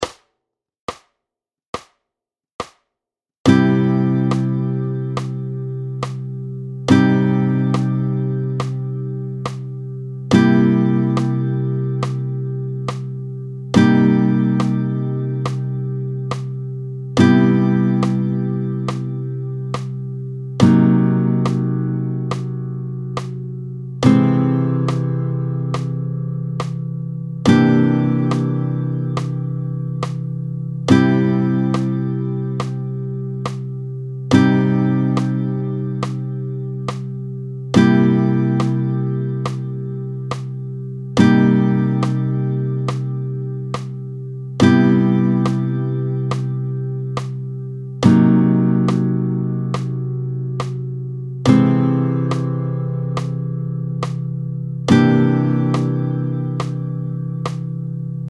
11-02 Les principaux accords de Do, tempo 70